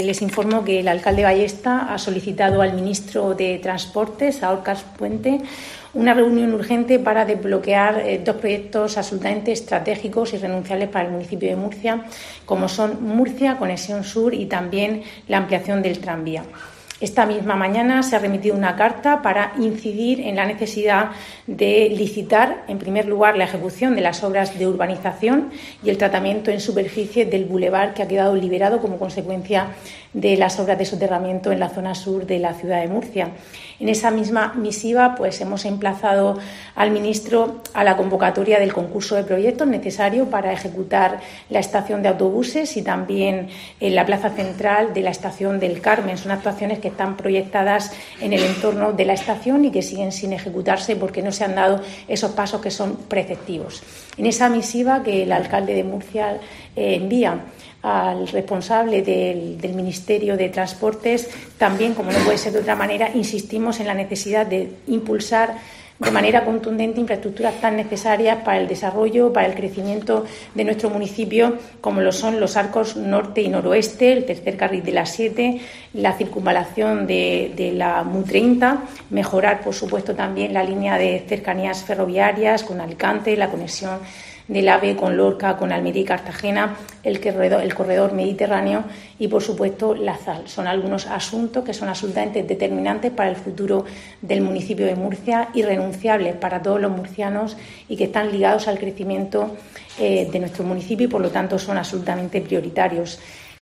Rebeca Pérez, vicealcaldesa de Murcia
Así lo ha comunicado este miércoles la vicealcaldesa y portavoz del Equipo de Gobierno, Rebeca Pérez, en una rueda de prensa en la que ha explicado que el alcalde se ha referido además en la misiva dirigida al ministro a "otras infraestructuras vitales para el crecimiento, desarrollo y progreso del municipio murciano".